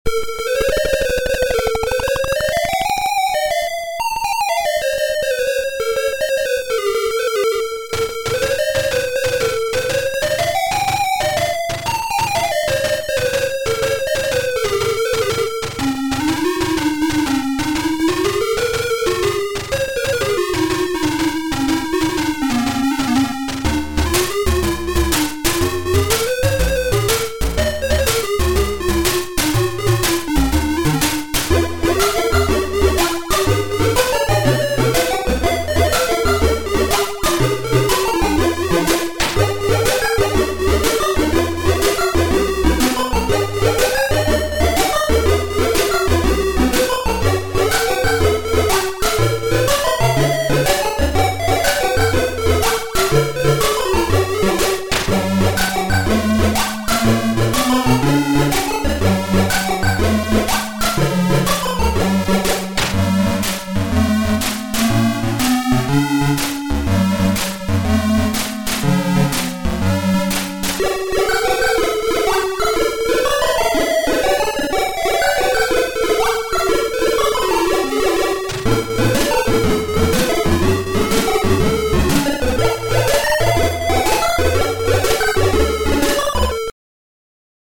Вот собственно задался целью написать эмуляцию AY на микроконтроллере, выкладываю первые успехи: звукозапись сделана с линейного входа звуковой карты, на фото собранное устройство (плата правда изначально не для него предназначалась).